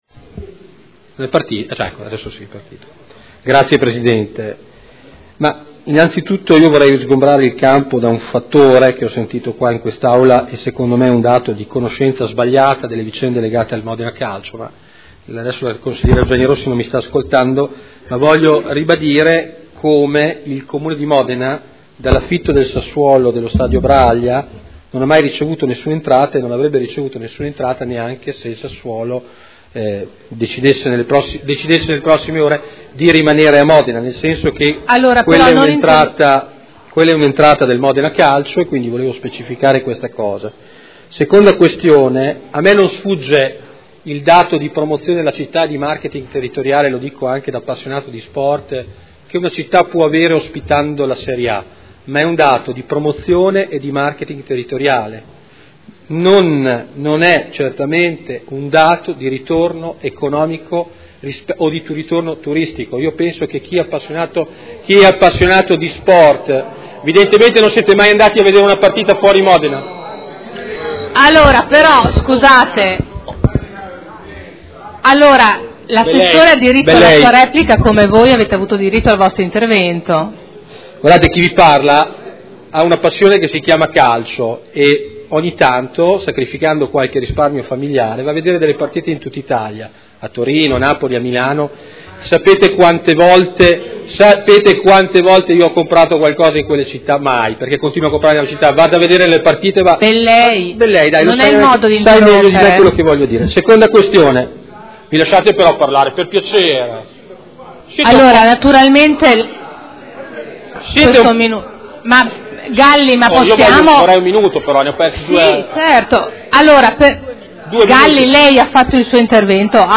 Antonino Marino — Sito Audio Consiglio Comunale
Seduta del 03/06/2013.